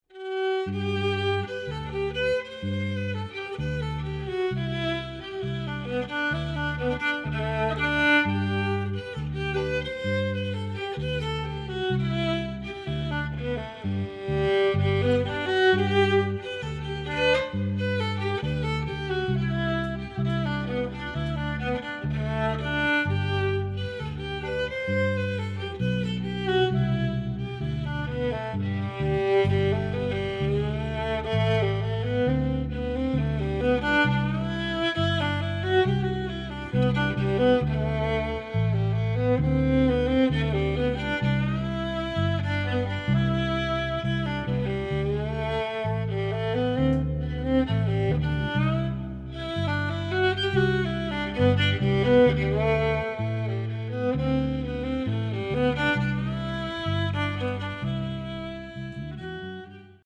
violin, accordion, viola, guitar, & vocals
French horn, electric bass